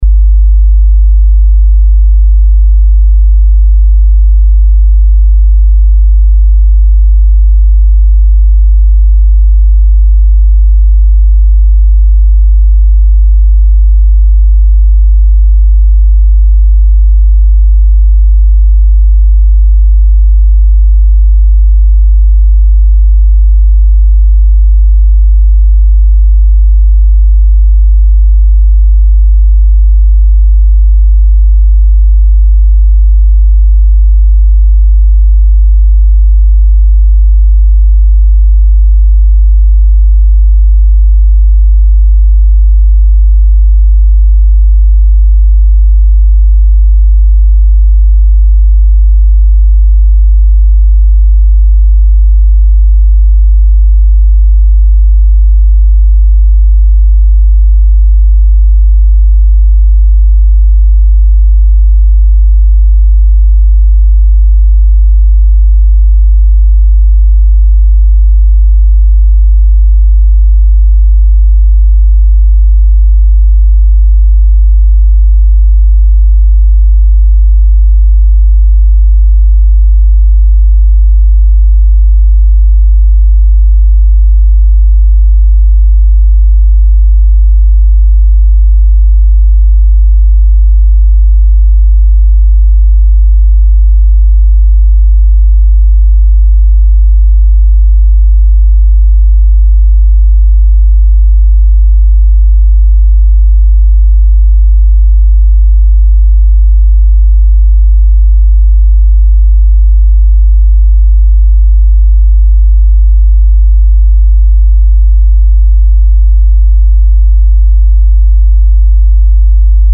Вложение 719776 Загоняете на флэшку тест 50 Гц 0 дб ( 50 Гц все тестеры измеряют на-ура, с другими частотами могут быть проблемы) Эквалайзер и фильтры выставляются в ноль. Уровень добавляется ручкой громкости до появления слышимых характерных искажений, Вызванных ограничением сигнала, затем производится измерение.